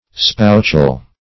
spoutshell - definition of spoutshell - synonyms, pronunciation, spelling from Free Dictionary
Search Result for " spoutshell" : The Collaborative International Dictionary of English v.0.48: Spoutshell \Spout"shell`\ (-sh[e^]l`), n. (Zool.)